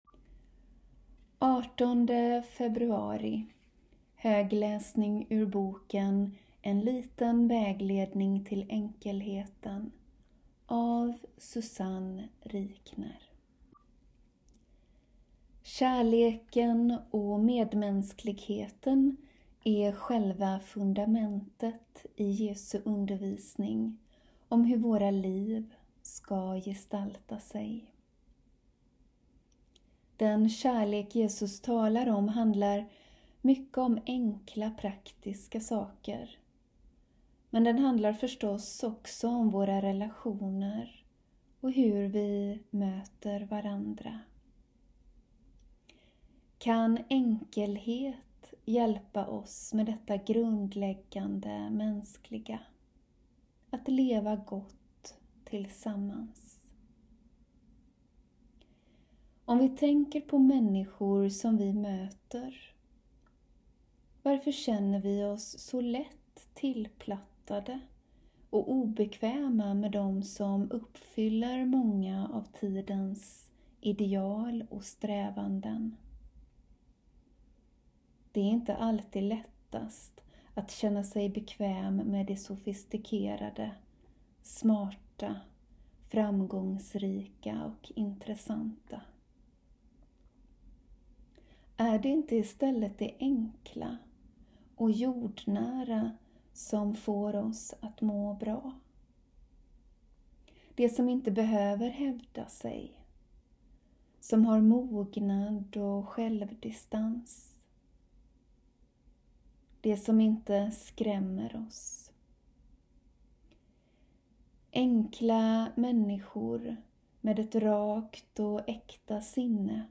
Daglig läsning från klostret ur boken En liten vägledning till enkelheten av Susanne Rikner